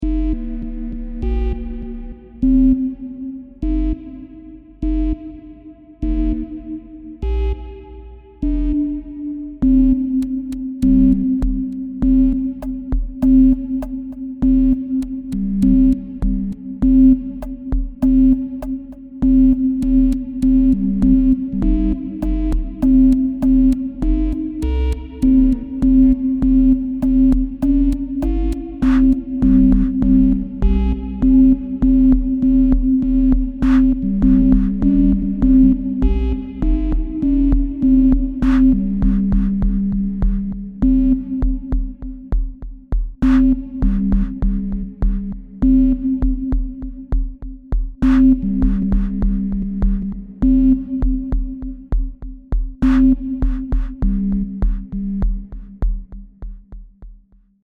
Pieza de Ambient Techno
Música electrónica
tecno
melodía
sintetizador